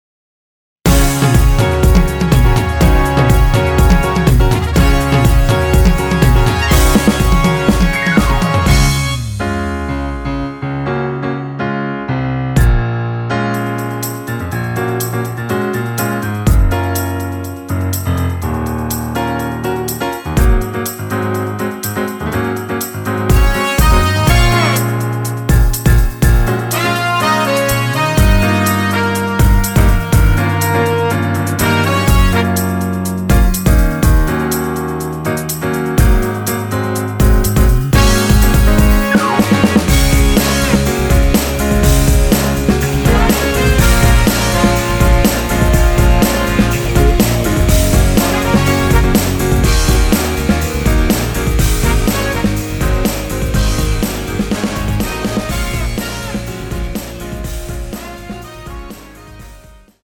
원키에서(-1)내린 (1절+후렴)으로 진행되는 멜로디 포함된 MR입니다.(미리듣기 확인)
Db
◈ 곡명 옆 (-1)은 반음 내림, (+1)은 반음 올림 입니다.
앞부분30초, 뒷부분30초씩 편집해서 올려 드리고 있습니다.
중간에 음이 끈어지고 다시 나오는 이유는